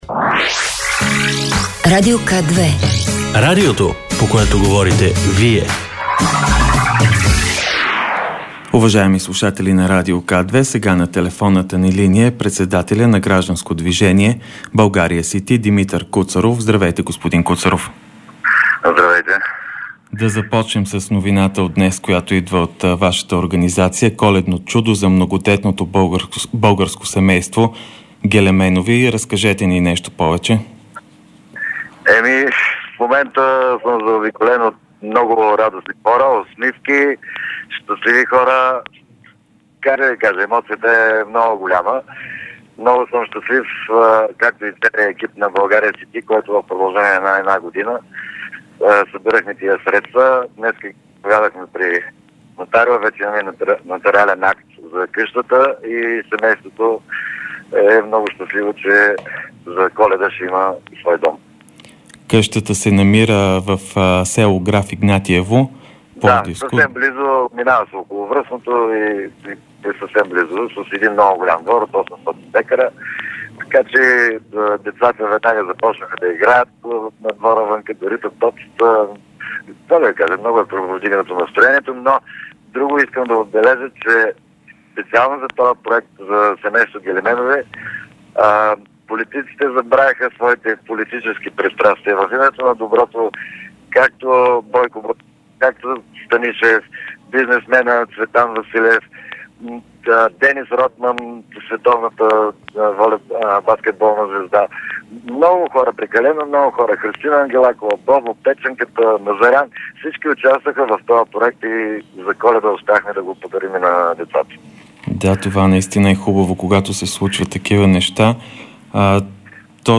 Радио К2 - директно от мястото на събитието
12.45 - Пресконференция след пленум на НС на БСП.